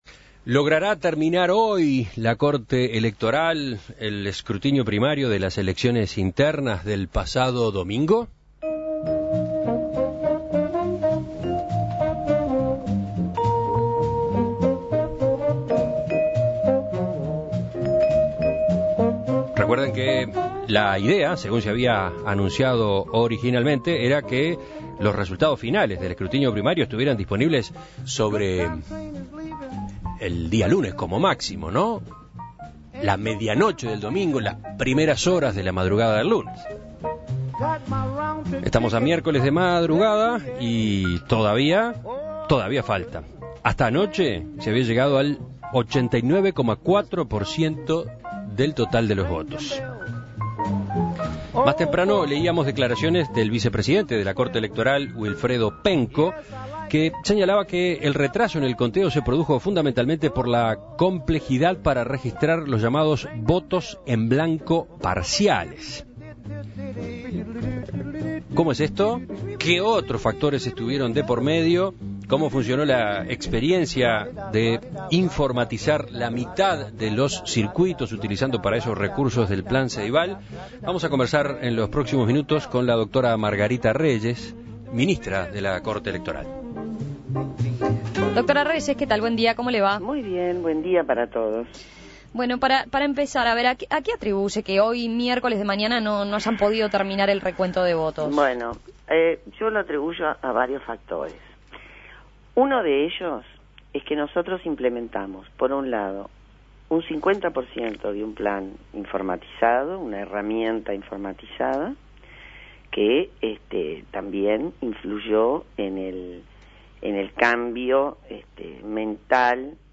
Una de las novedades de esta instancia electoral fue la informatización de la mitad de los circuitos del país, pero algunos desperfectos tecnológicos también generaron complicaciones en esa área. En Perspectiva dialogó al respecto con Margarita Reyes, ministra de la Corte Electoral.